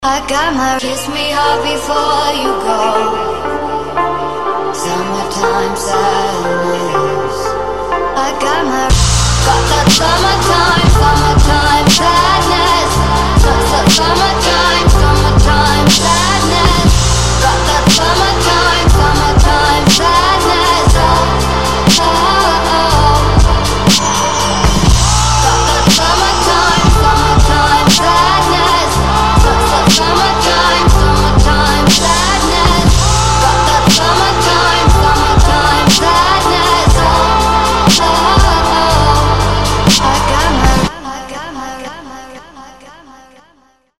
• Качество: 320, Stereo
красивые
Хип-хоп
женский голос
спокойные
Bass